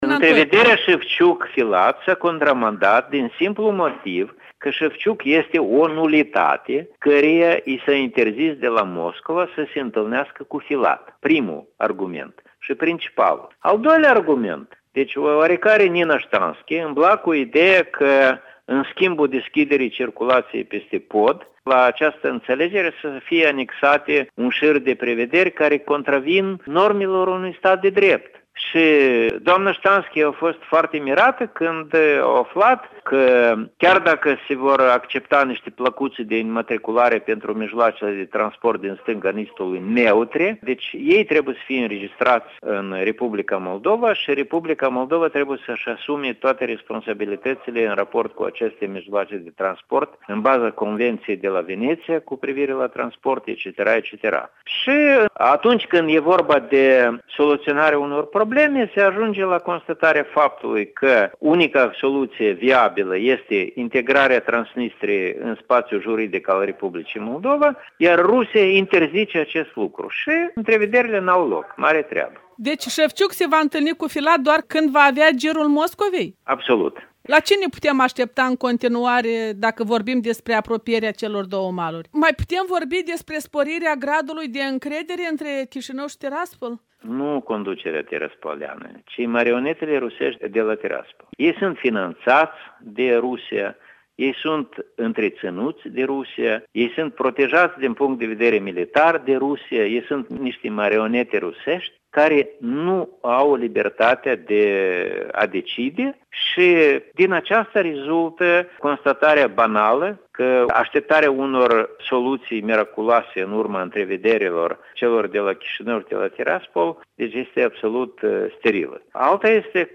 Interviu la orele dimineții: cu Oazu Nantoi despre negocierile 5+2